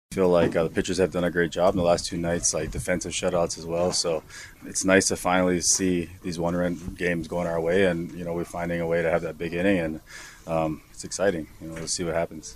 The Pirates scratched out a run in the second inning and it was still only 1-0 before they scored four in the seventh.  Shortstop Isiah Kiner-Falefa is enjoying being on the winning end of tight games.